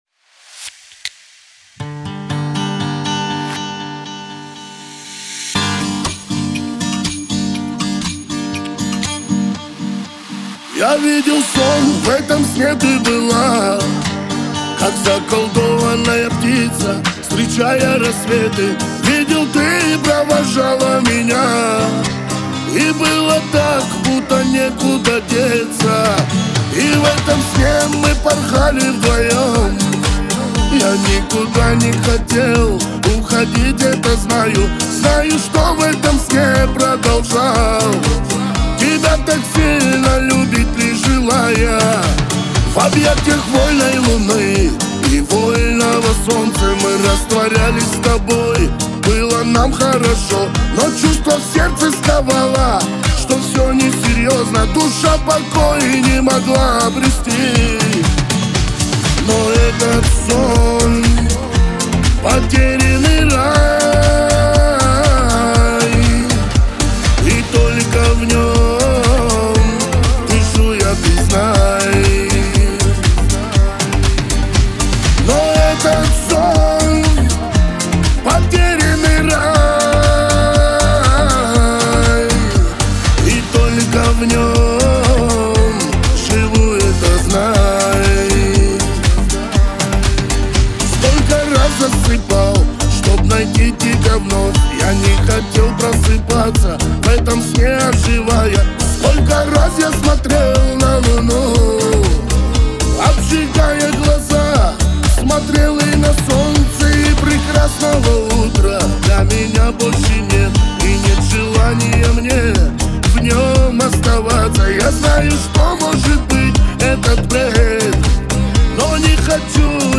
Лирика
Кавказ поп